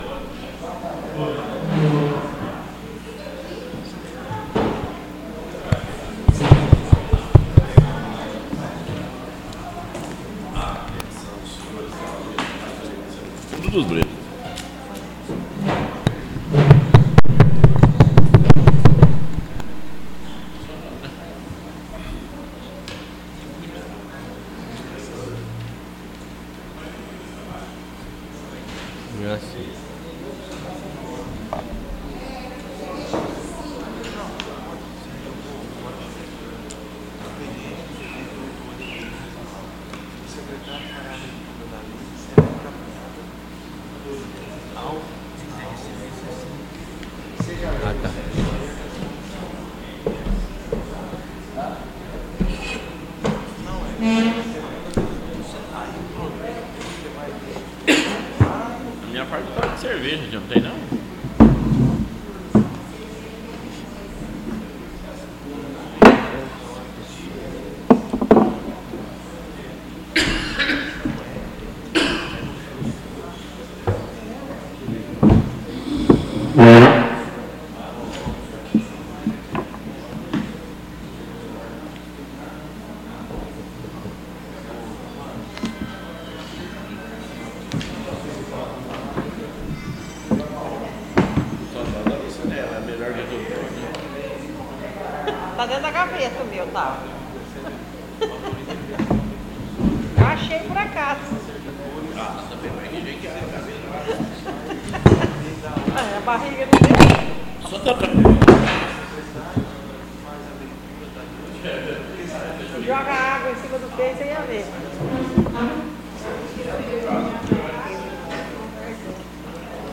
Áudio da 5° Sessão Ordinária – 04/04/2017